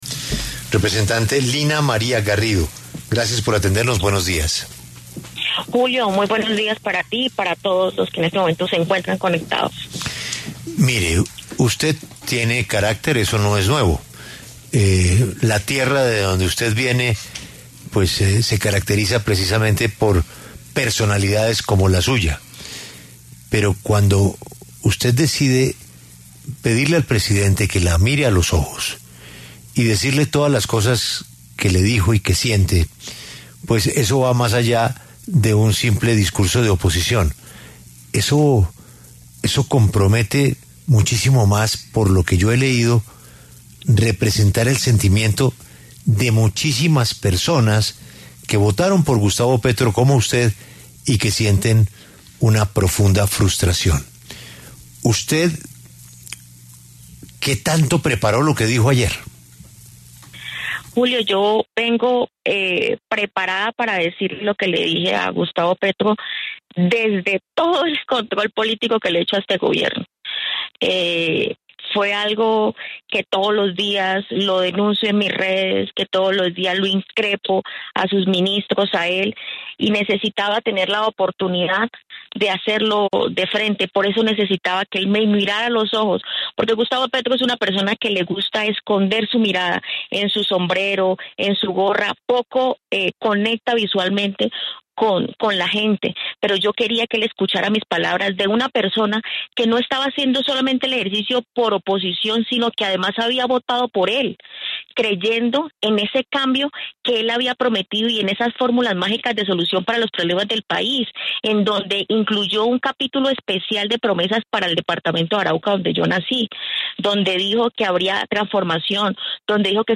La representante Lina María Garrido, de Cambio Radical, pasó por los micrófonos de La W y habló sobre su discurso de réplica al presidente Gustavo Petro, en el marco de la cuarta y última legislatura de este cuatrenio. Garrido ratificó sus críticas y ofreció algunos detalles de la construcción de su discurso.